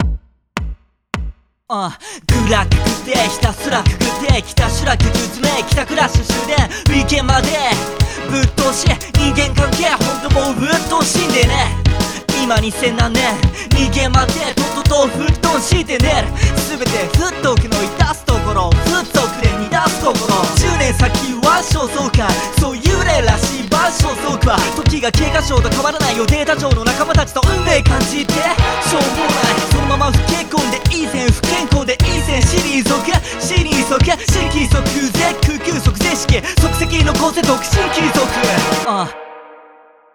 [B P M ] 105
キックの処理がむずい。欲張りすぎると潰れる。